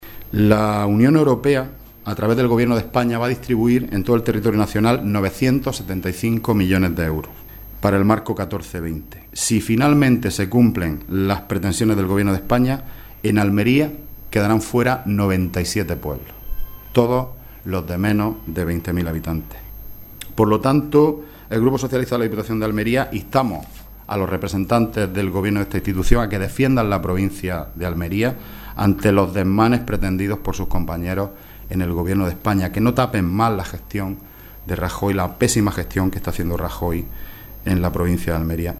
Rueda de prensa celebrada por el portavoz del PSOE en la Diputación Provincial, Juan Antonio Lorenzo